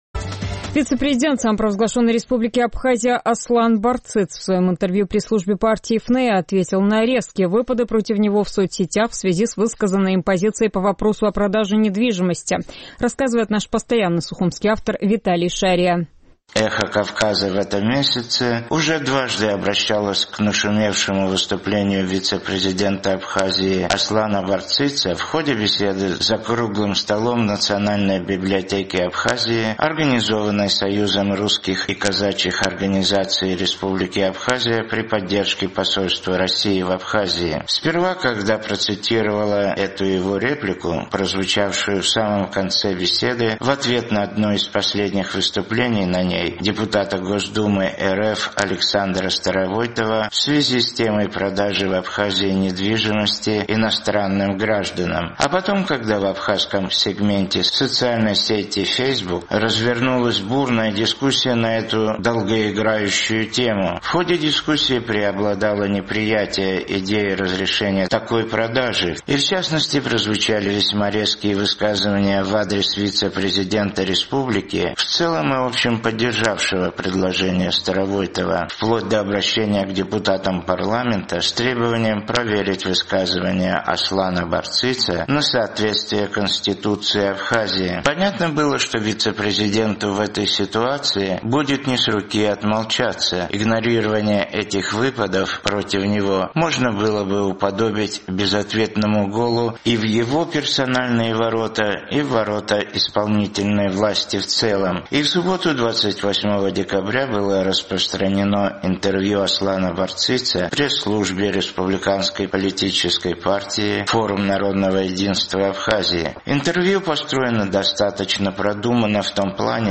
Вице-президент Абхазии Аслан Барциц в своем интервью пресс-службе партии «ФНЕА» ответил на резкие выпады против него в соцсетях в связи с высказанной им позицией по вопросу о продаже недвижимости иностранцам.